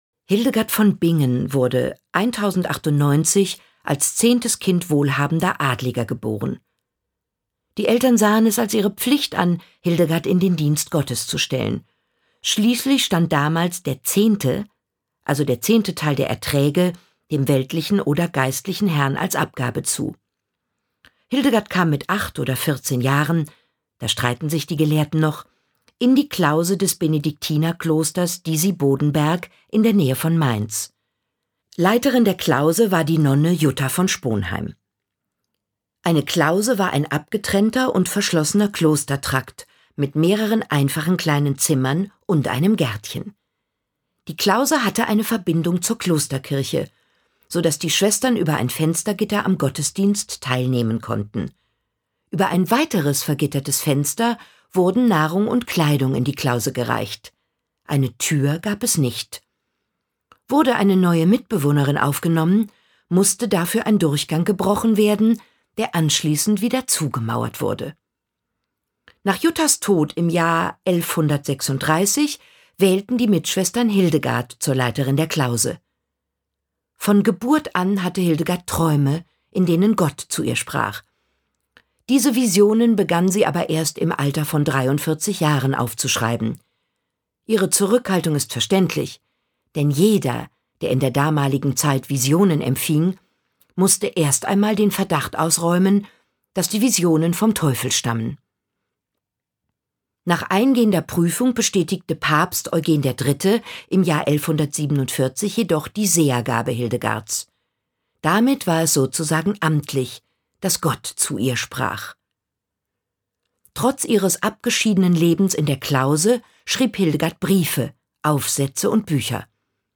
Schlagworte Ärzte • Audio-CD, Kassette / Kinder- und Jugendbücher/Sachbücher, Sachbilderbücher • Hörbuch für Kinder/Jugendliche • Hörbuch für Kinder/Jugendliche (Audio-CD) • Medizin • Medizingeschichte • Medizin; Kindersachbuch/Jugendsachbuch • Medizin; Kindersachbuch/Jugendsachbuch (Audio-CD)